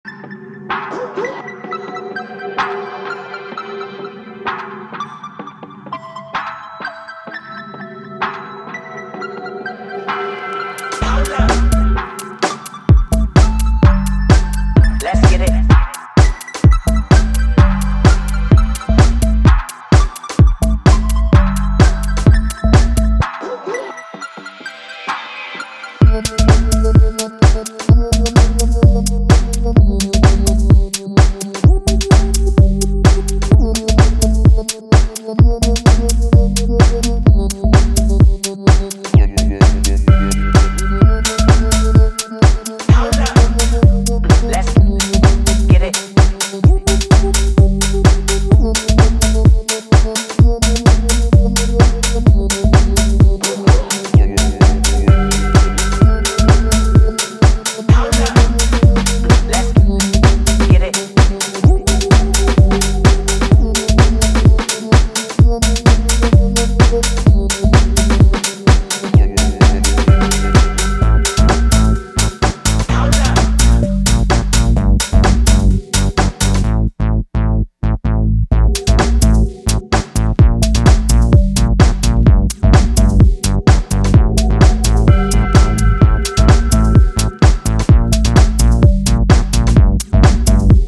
中毒性の高いメロディーと跳ねたキックでキープ力と浮遊感を兼ね備えた